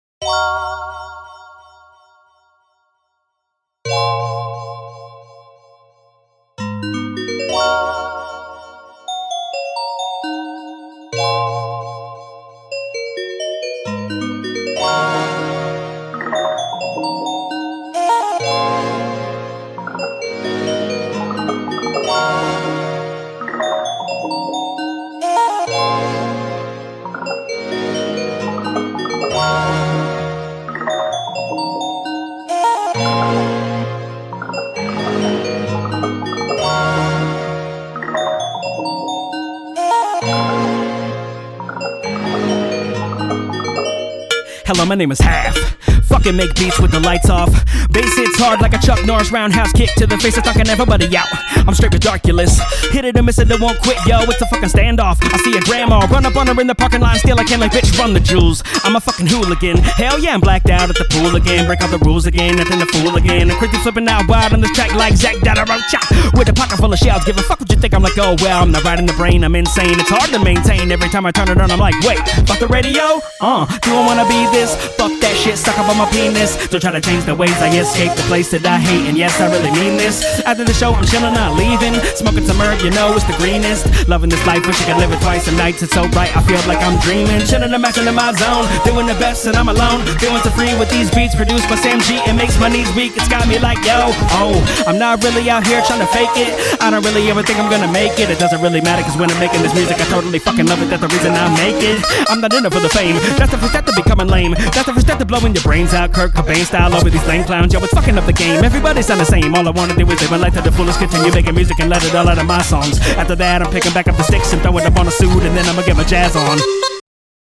Hiphop
Description : machine gun, progressive, new age sound, hiph